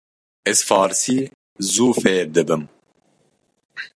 Uitgespreek as (IPA) /fɑːɾɪˈsiː/